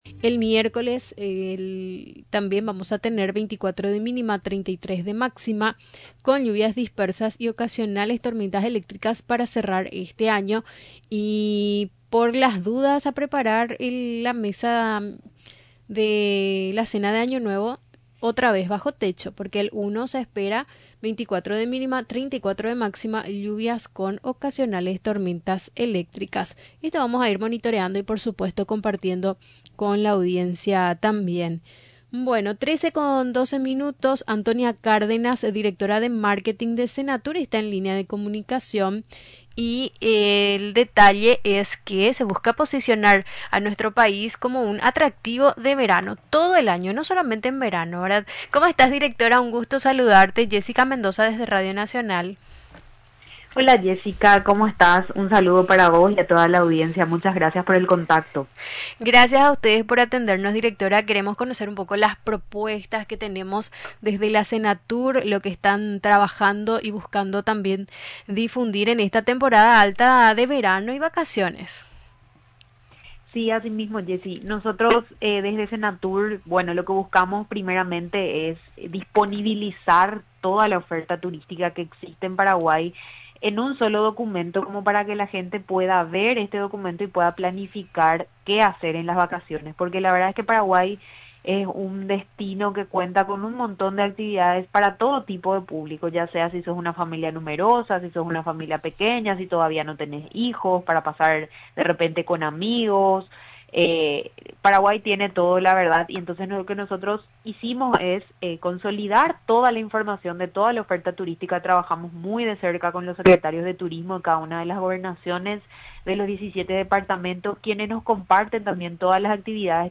Durante la entrevista en Radio Nacional del Paraguay, explicó que en la página web de la institución, los interesados pueden encontrar los lugares, costos y horarios de atención.